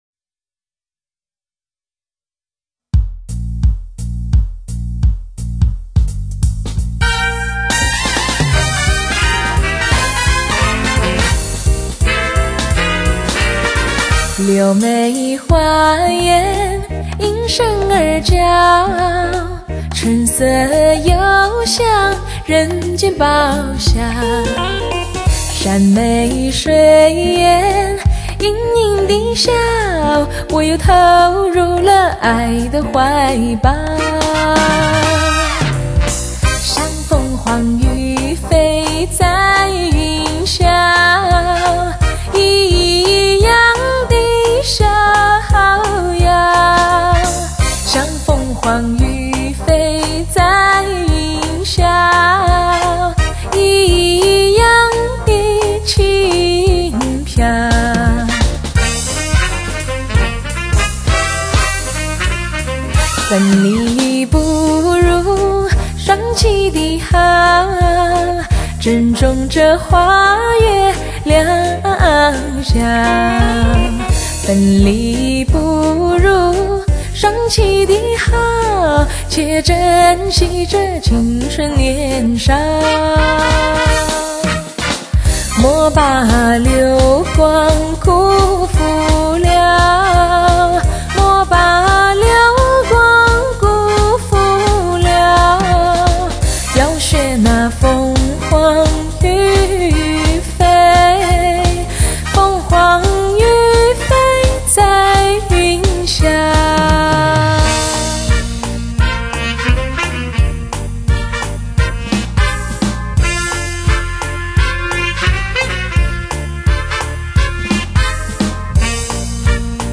十大年度发烧唱片唯一指定试机监听女声碟皇